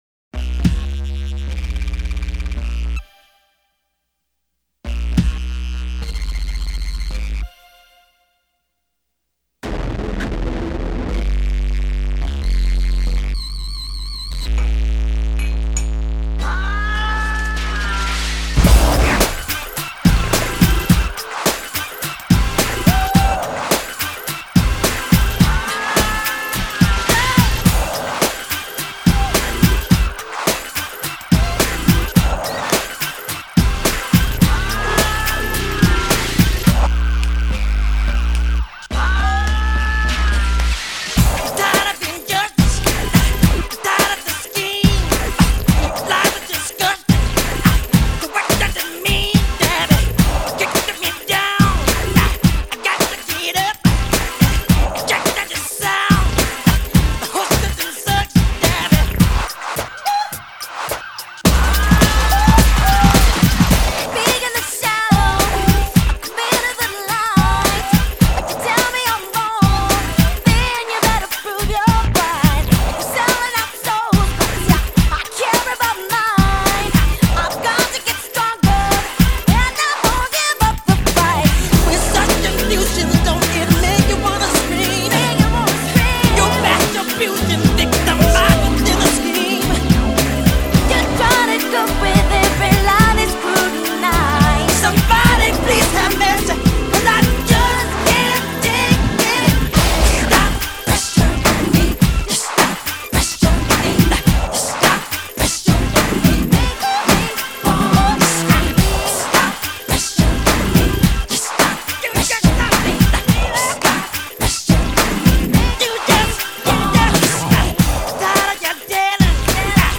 Жанр: Soul